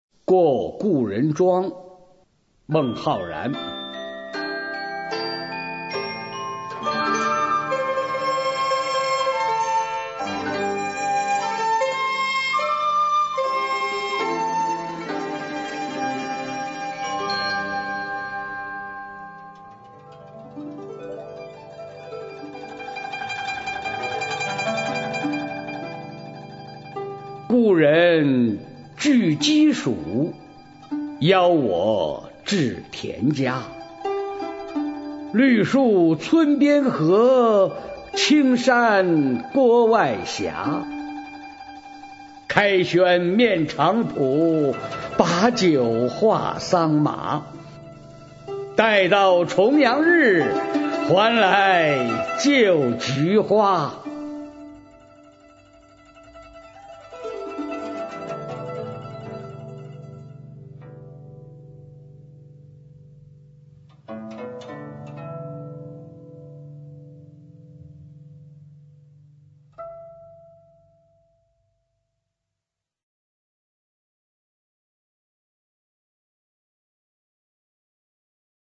《过故人庄》原文、译文、赏析（含朗读）